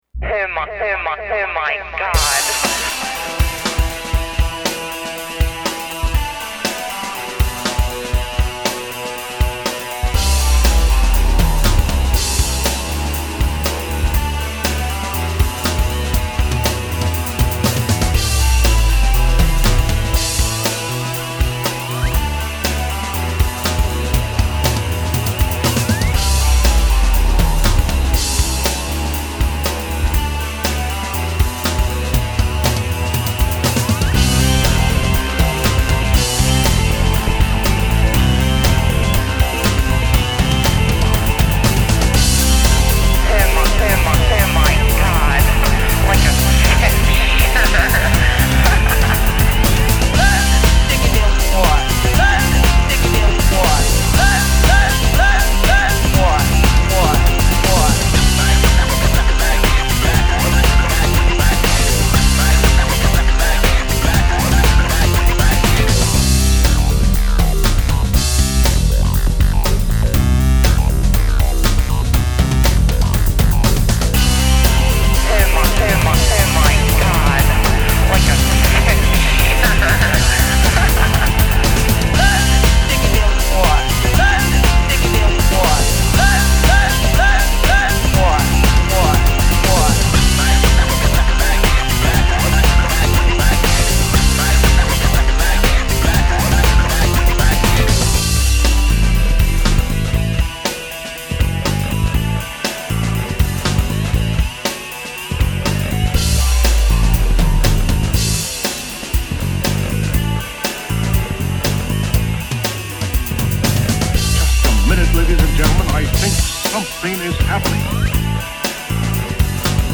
Electronica
Big beat rocking track, upbeat good party music.